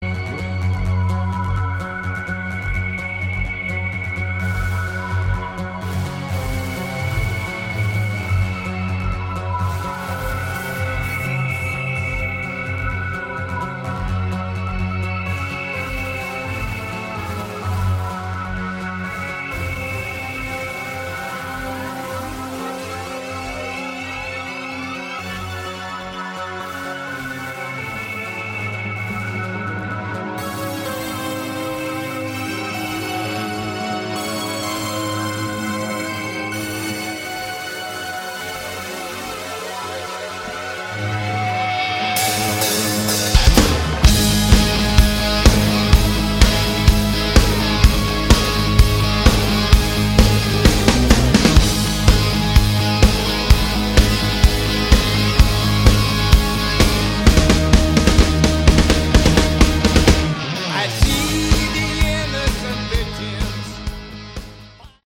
Category: Hard Rock
vocals
bass
guitar
drums